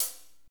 HAT F S C08L.wav